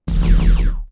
beam.wav